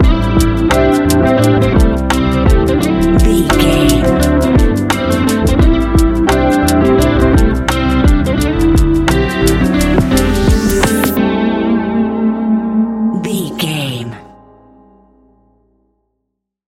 Ionian/Major
A♭
chilled
laid back
Lounge
sparse
new age
chilled electronica
ambient
atmospheric